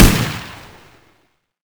ump45_shoot.wav